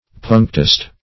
\Punc"tist\